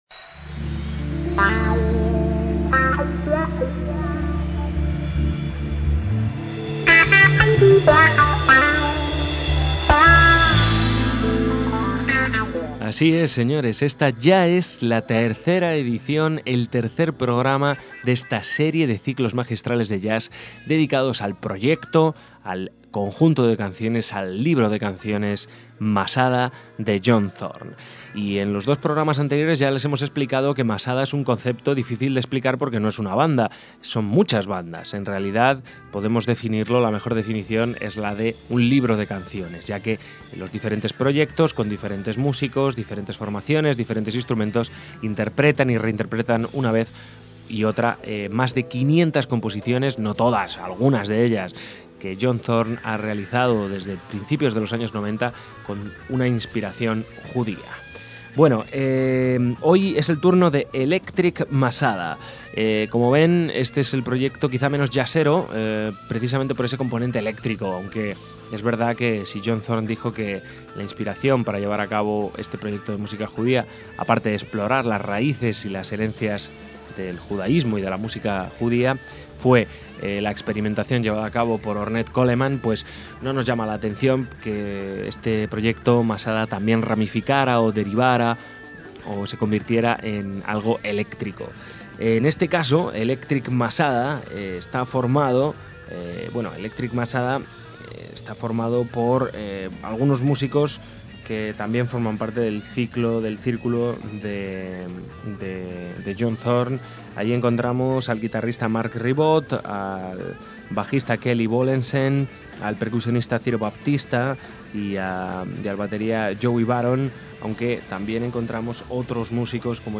el conjunto dominado por instrumentos eléctricos
saxo alto
guitarra eléctrica
teclados
bajo eléctrico
batería
percusión